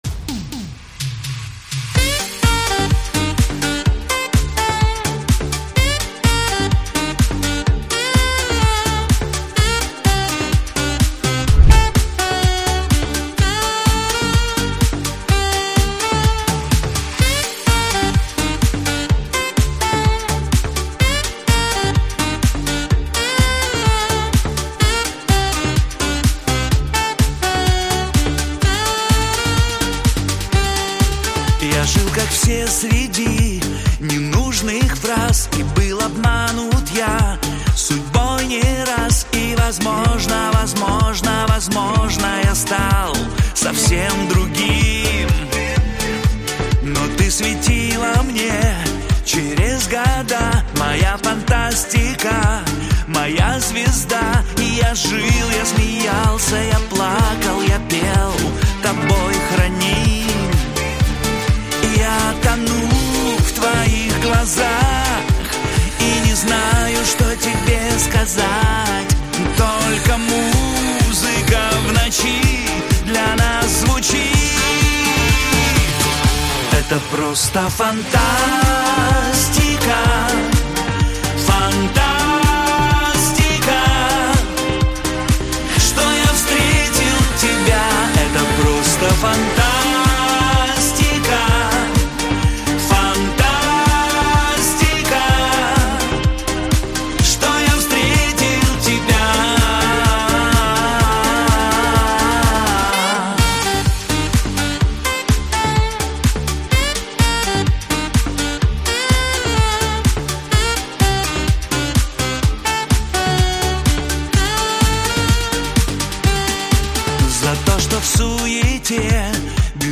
Живое исполнение